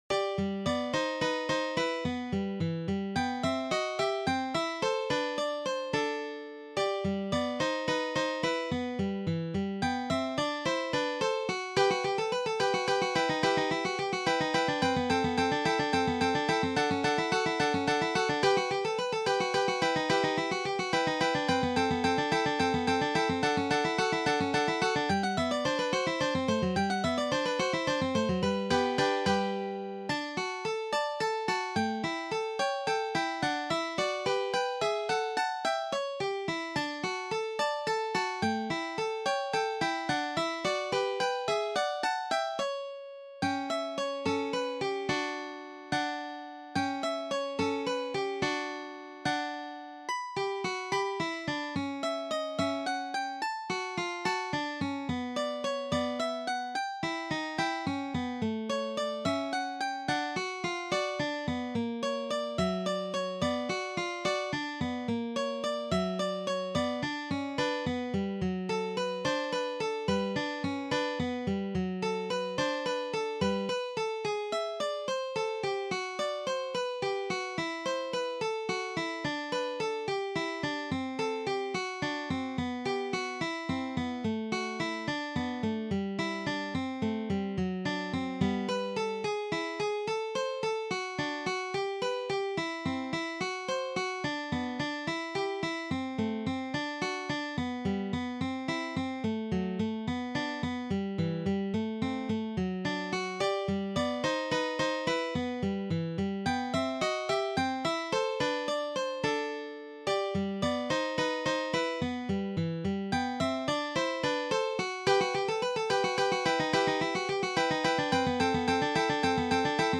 Duet No. 10 by Ferdinando Carulli is arranged for two guitars in the key of G major. The extended range is high B, first string, seventh fret. Rhythms are advanced level, mostly sixteenth notes. There are many accidentals.